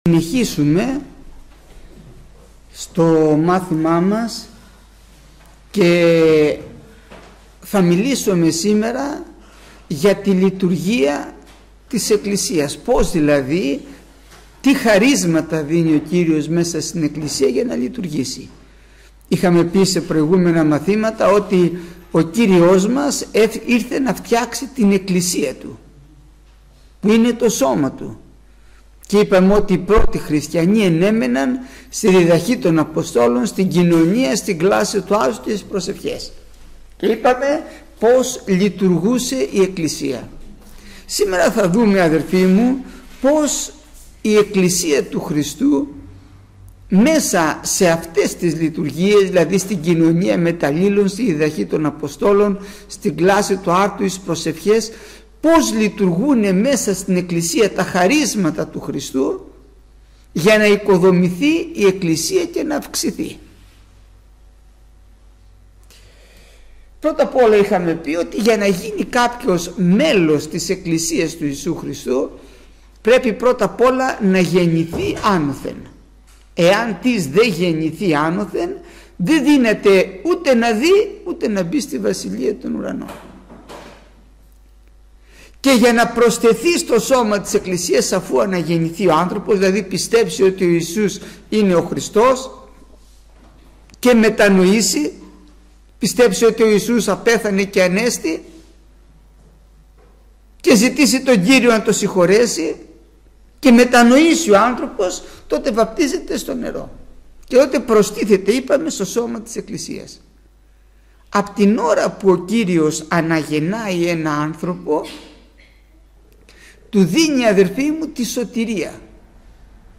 Γεννηθήτω το θέλημά σου (η εκκλησία) – Μάθημα 87ο